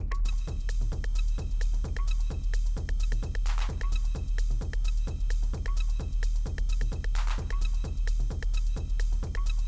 Identical setup on channel 3 of the b6 (everything is setup up the same except I switched the cable from mono channel 1 to mono channel 3 and select channel 3 in Ableton):